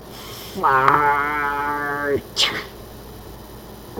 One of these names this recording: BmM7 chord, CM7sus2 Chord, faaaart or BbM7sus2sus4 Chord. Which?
faaaart